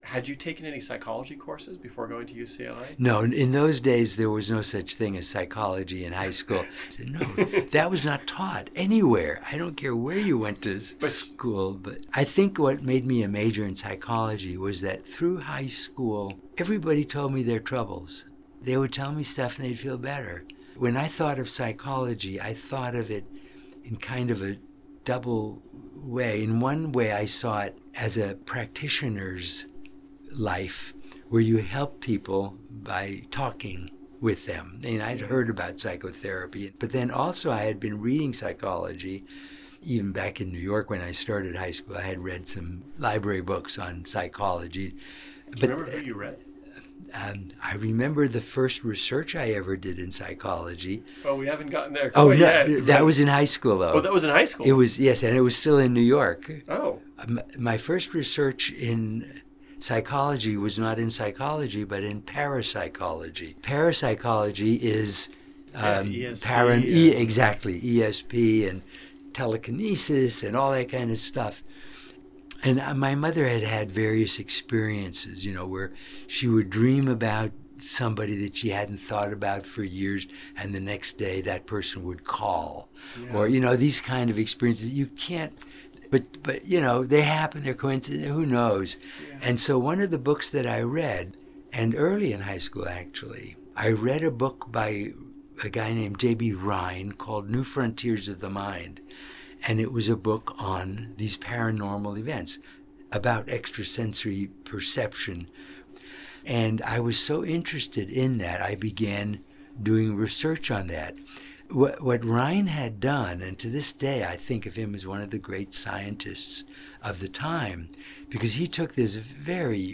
I am pleased to present some tidbits from our conversation — all recalled by Dr. Rosenthal with his characteristic kindness and joviality.